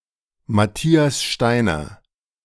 Matthias Steiner (German pronunciation: [maˈtiːas ˈʃtaɪnɐ]
De-Matthias_Steiner.ogg.mp3